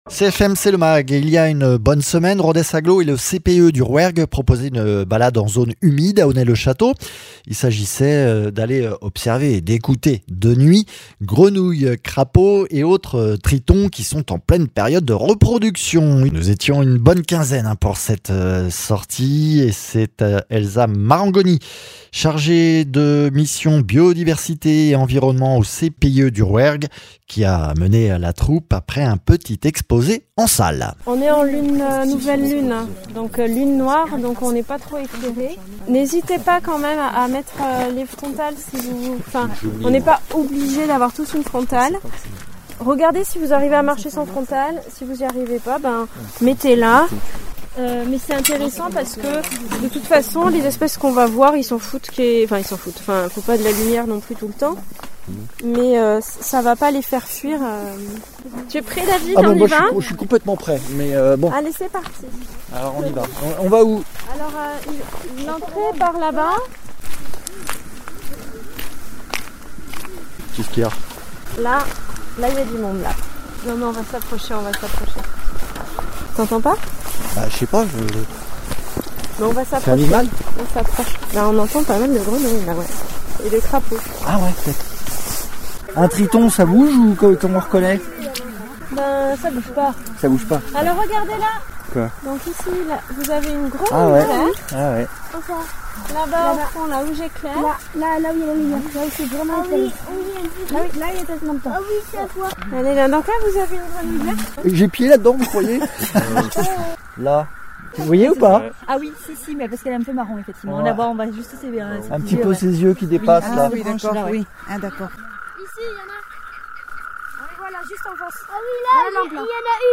Interviews
Reportage à Onet le Chateau à l’occasion de la sortie ’’un dragon dans mon jardin’’ avec le CPIE du Rouergue et Rodez agglo, il s’agissait d’aller observer et d’écouter, grenouilles, crapauds et autres tritons en période de reproduction.